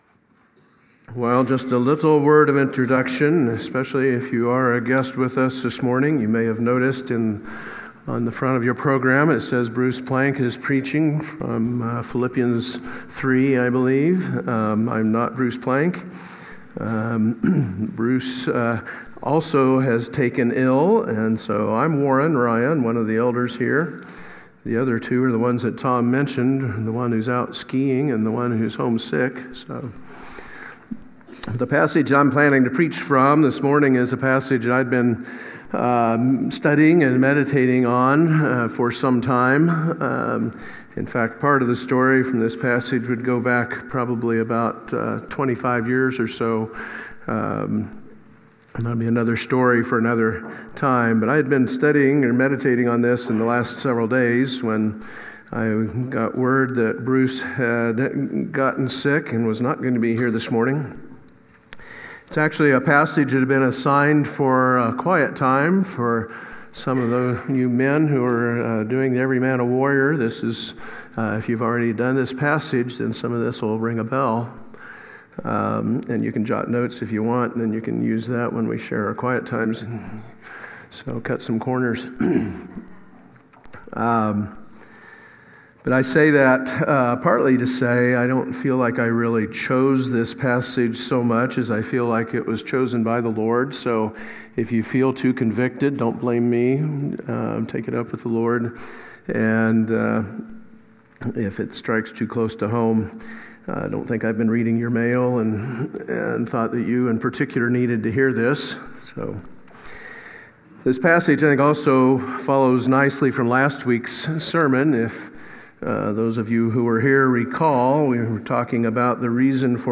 Sermons – Wichita Bible Church